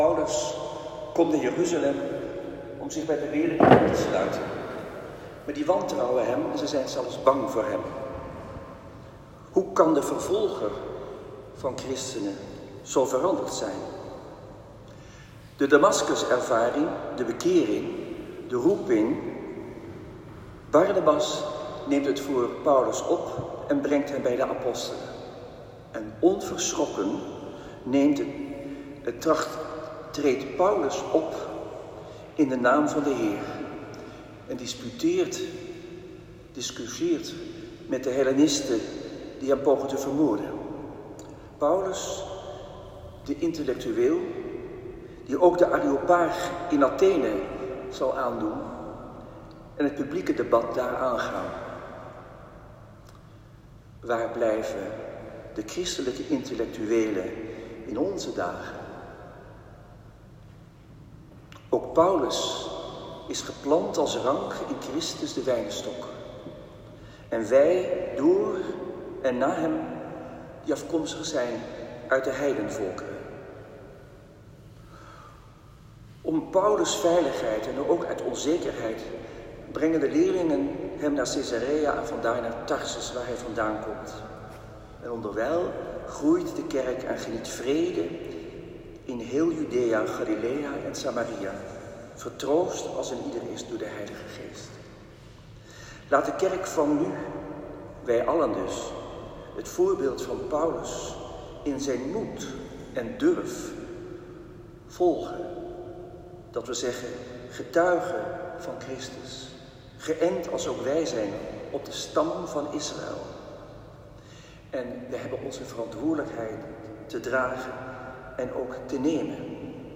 Celebrant Antoine Bodar.
Preek.m4a